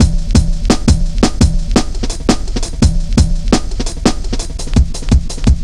Index of /90_sSampleCDs/Zero-G - Total Drum Bass/Drumloops - 3/track 55 (170bpm)